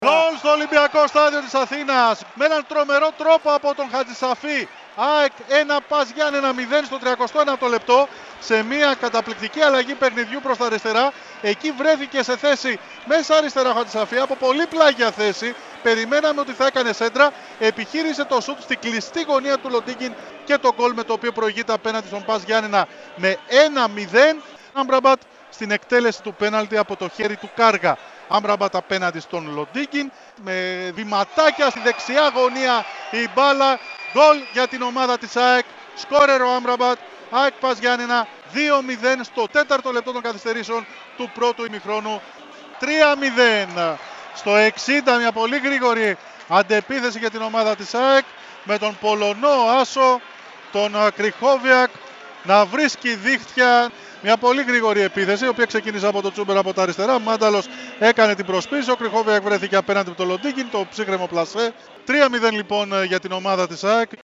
ΕΡΑΣΠΟΡ: Η περιγραφή των γκολ της 8ης αγωνιστικής των πλέι οφ (audio)